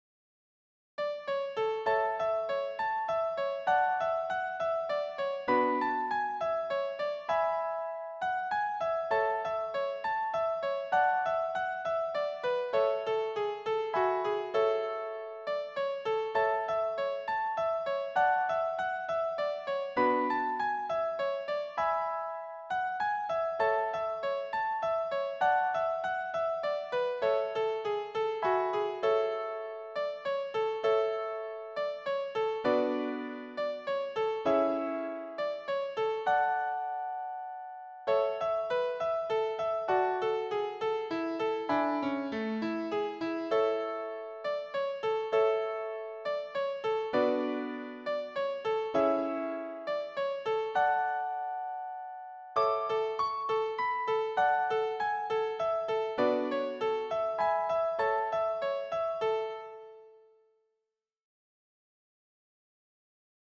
This waltz was first performed by the Groovemongers on a dance and dive trip in Bonaire. It should be played sprightly, but with some drama in the B part.
The demo version included here is slow to facilitate learning.